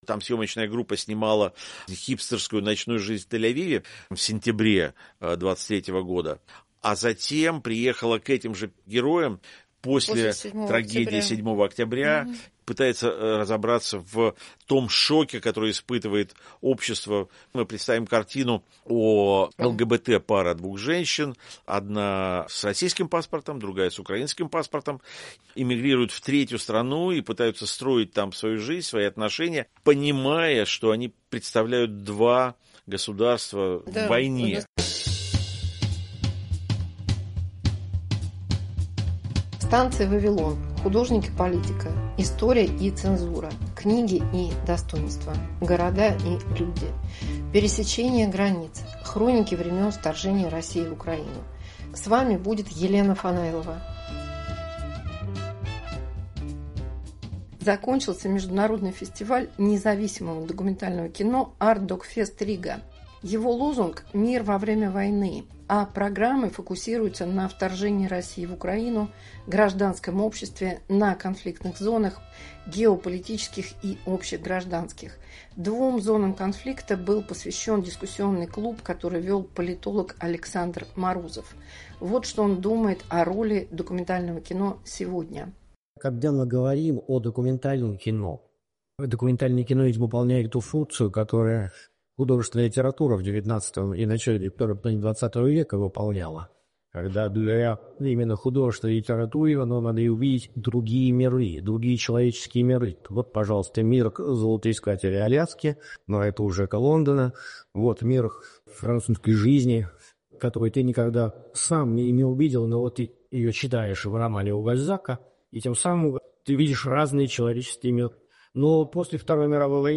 Дискуссия на Artdocfest/Riga 2025